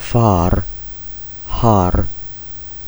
Long A - like the 'a' in 'fAther'  (far, har)